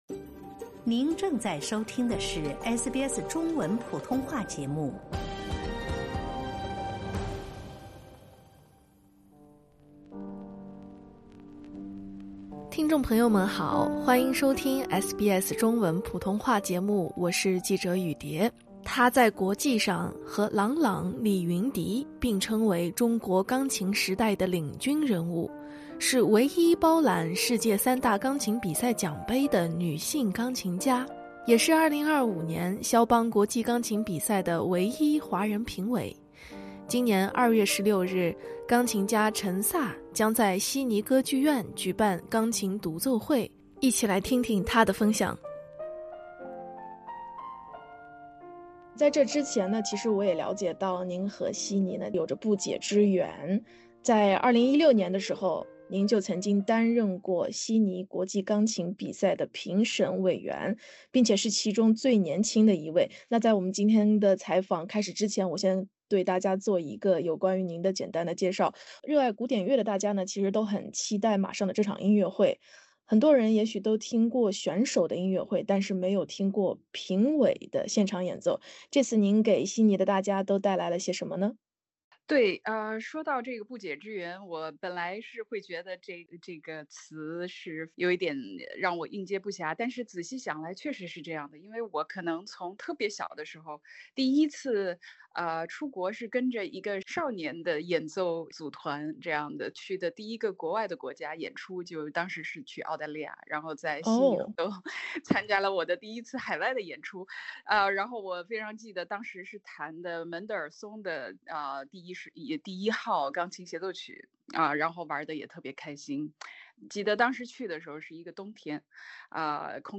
专访钢琴家陈萨：幻想与孤独在艺术道路上很重要